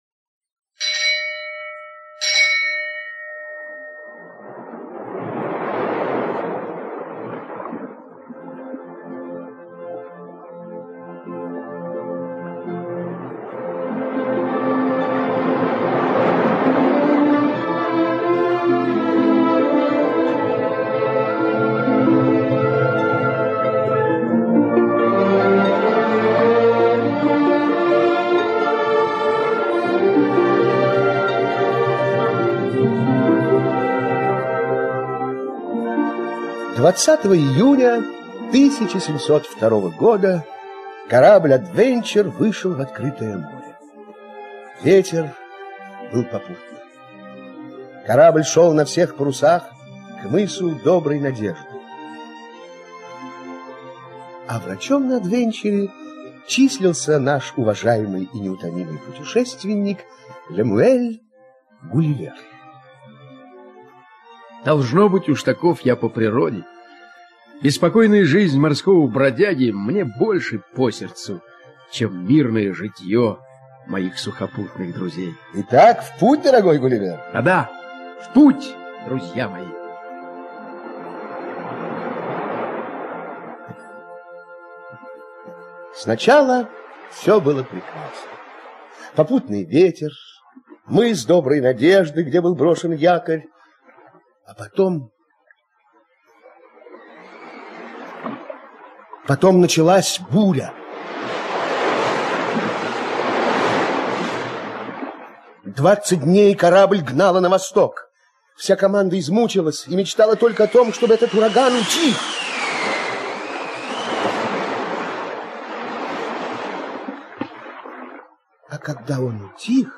Путешествие Гулливера в Бробдингнег - аудиосказка Свифта